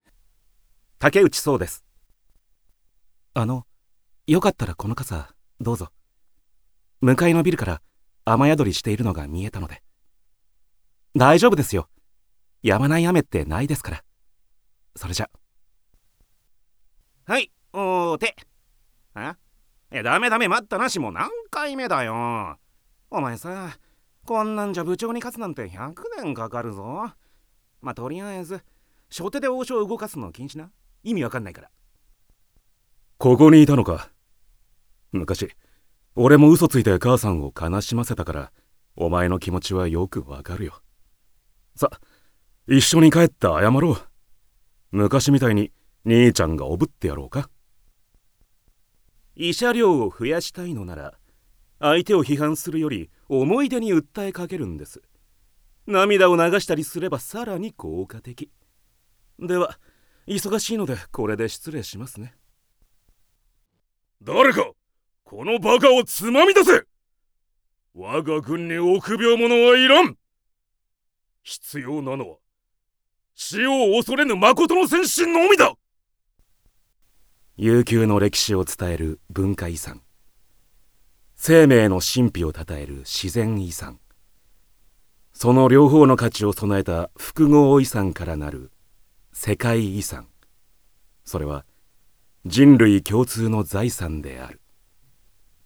Voice Sample
ボイスサンプル